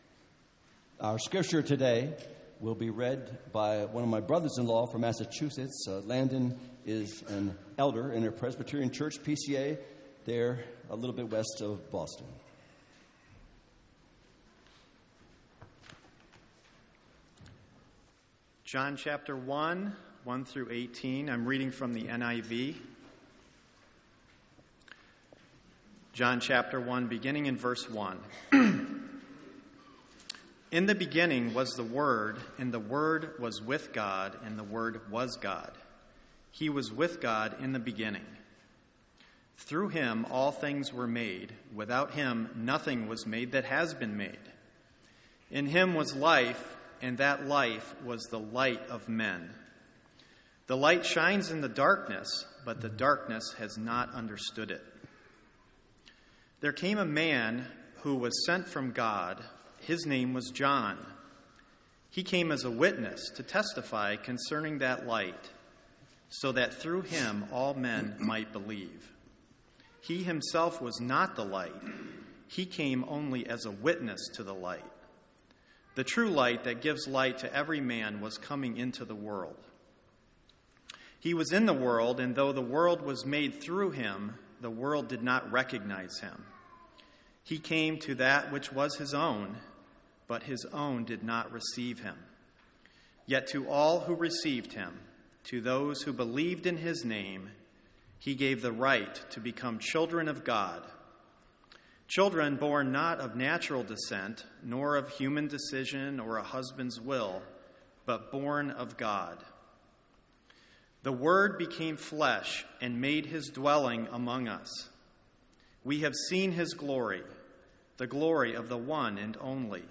Sermons on John 1:1-3 — Audio Sermons — Brick Lane Community Church